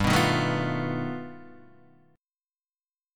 G Augmented 9th
G+9 chord {3 2 1 2 0 1} chord